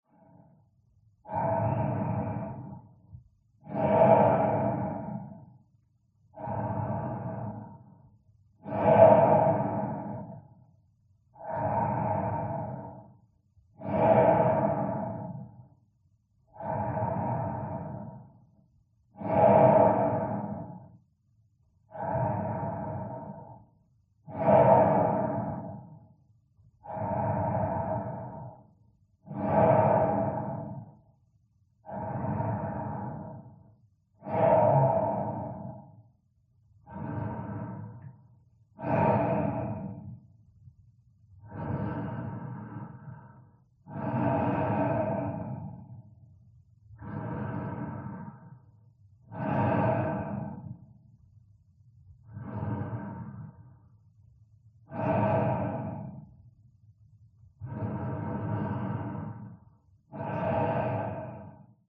Звук дыхания человека в скафандре